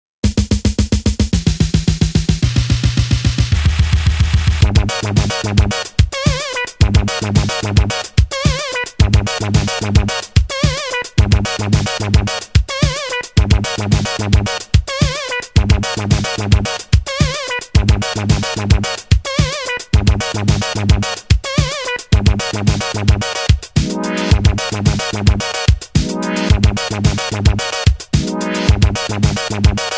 • Electronic Ringtones